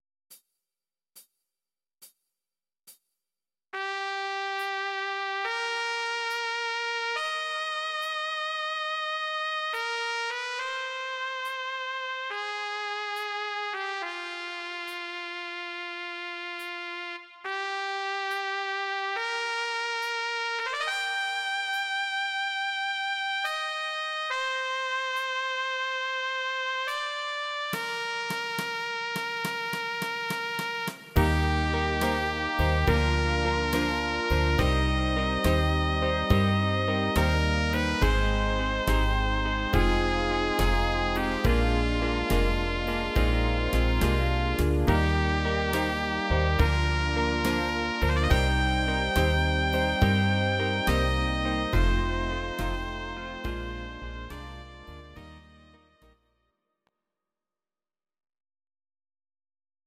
These are MP3 versions of our MIDI file catalogue.
Please note: no vocals and no karaoke included.
Trompete